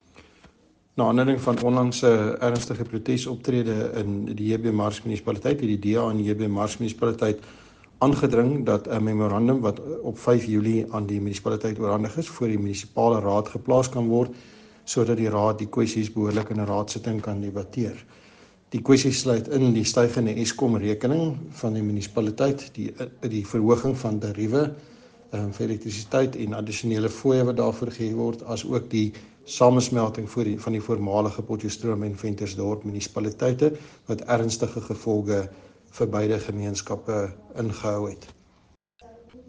Note to Broadcasters: Please find attached soundbites in
English and Afrikaans by Hans-Jurie Moolman